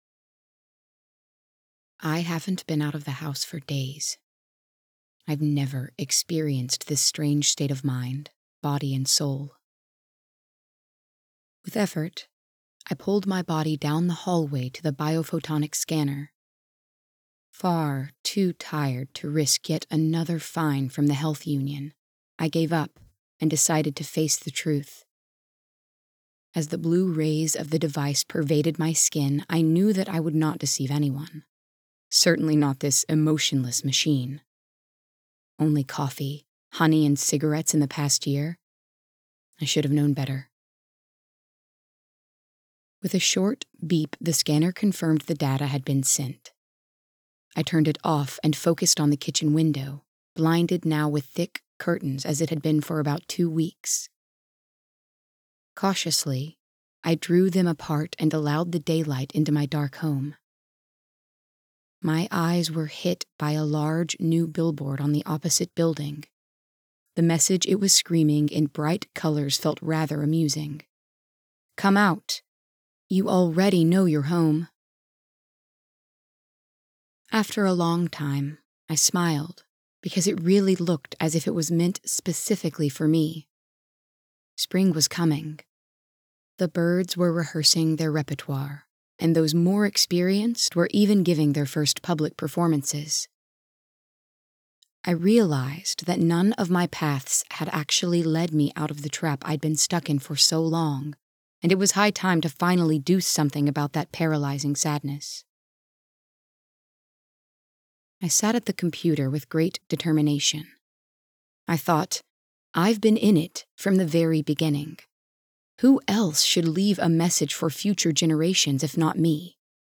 Roden24 (EN) audiokniha
Ukázka z knihy